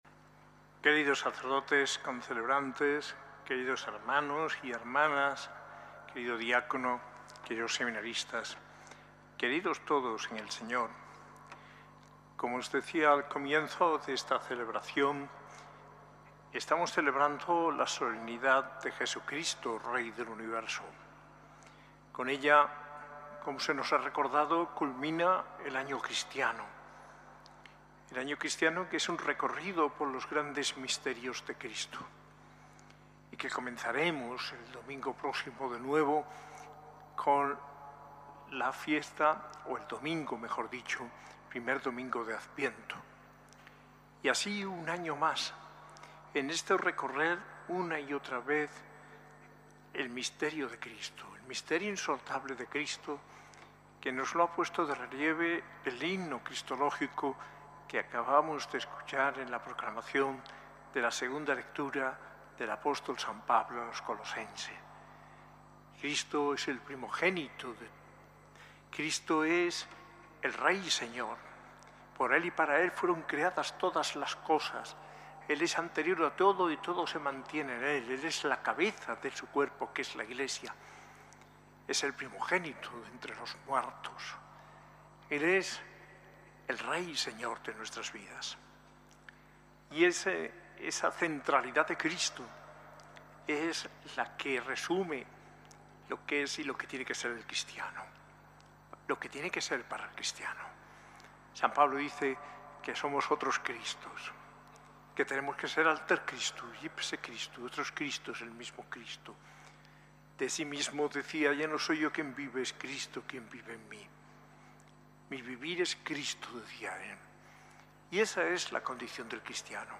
Homilía de Mons. José María Gil Tamayo, arzobispo de Granada, en la solemnidad de Cristo Rey del Universo, el 23 de noviembre de 2025, en la S. A. I. Catedral de Granada.